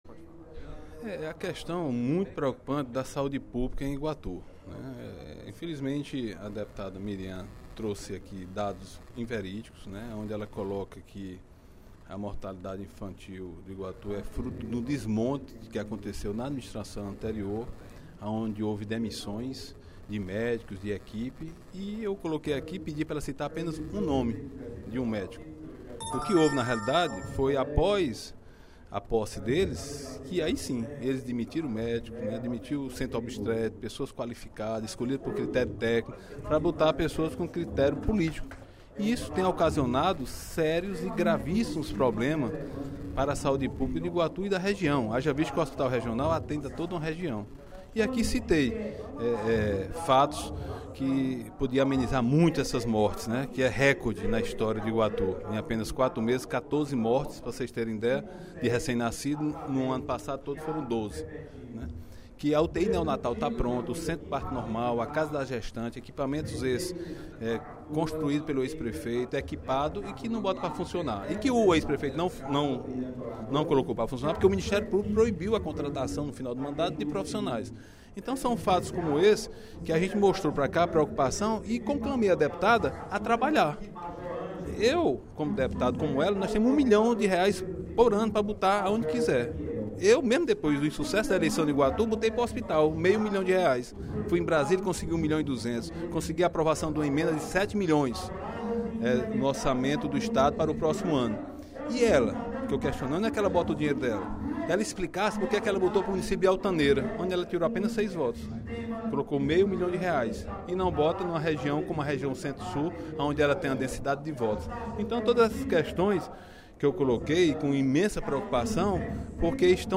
O deputado Agenor Neto (PMDB) esclareceu, durante o primeiro expediente da sessão plenária desta terça-feira (16/05), medidas adotadas na área de saúde pela gestão anterior de Iguatu.
A deputada Mirian Sobreira, em aparte, reafirmou que as informações apresentadas por ela são verdadeiras.